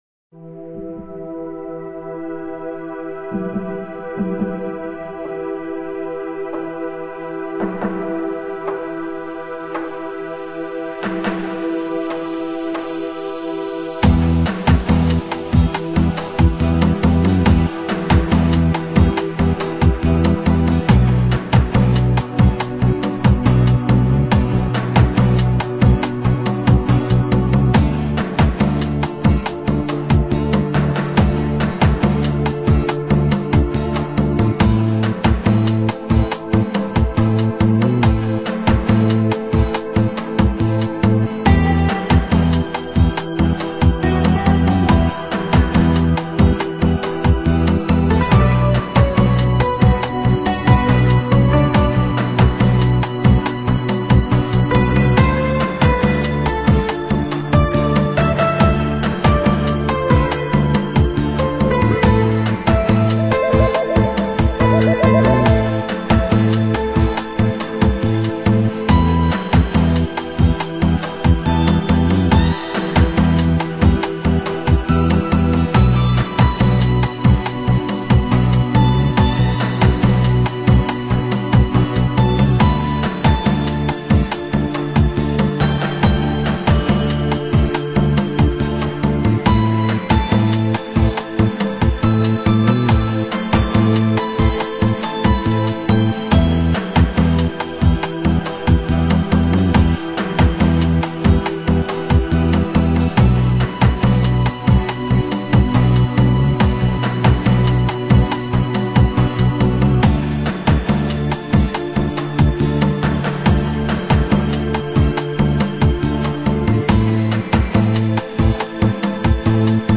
Chill Out、Lounge 音乐